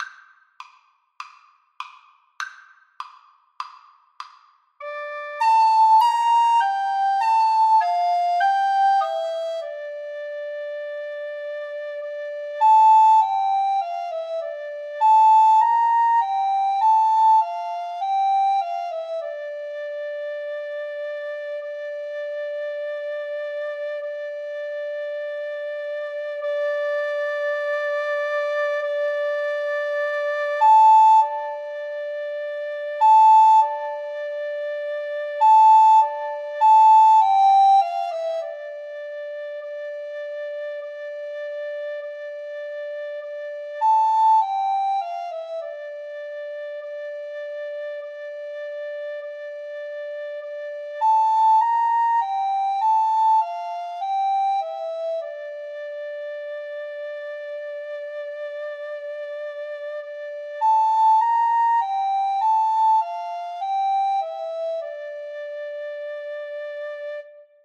4/4 (View more 4/4 Music)
Recorder Duet  (View more Easy Recorder Duet Music)
Film (View more Film Recorder Duet Music)